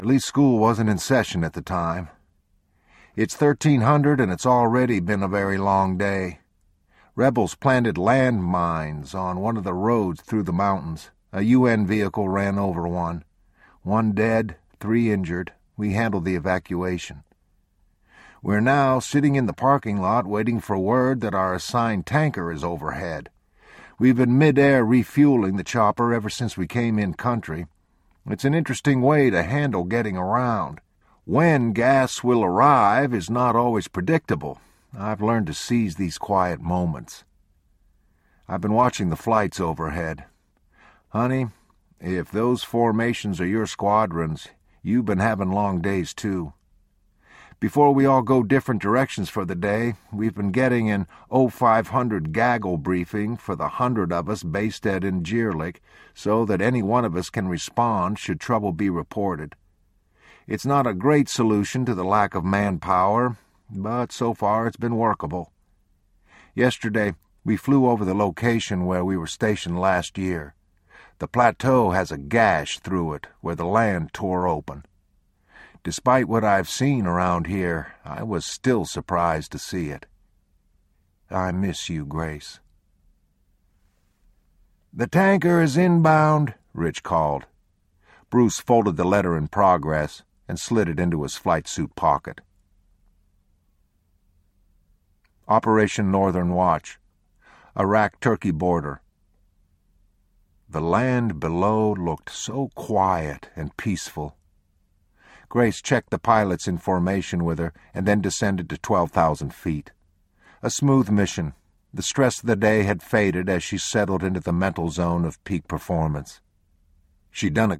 True Valor Audiobook
Narrator